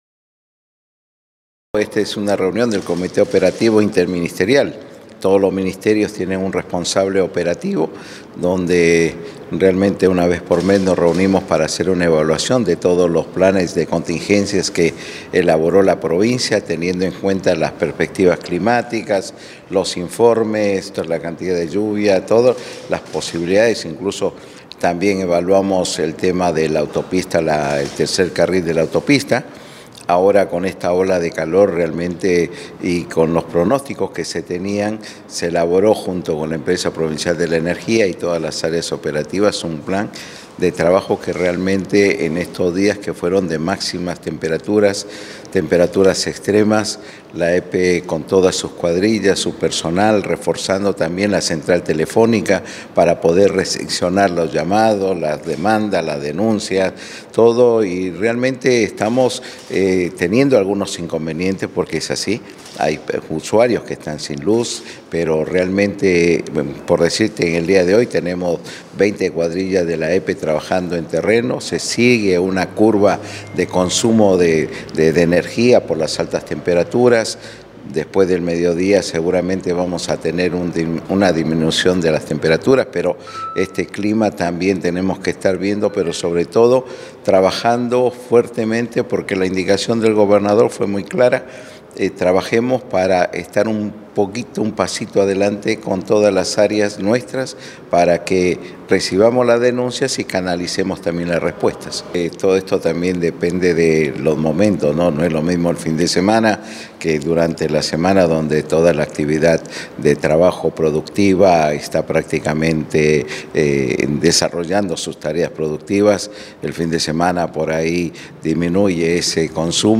Marcos Escajadillo, secretario de Protección Civil y Gestión de Riesgo de la Provincia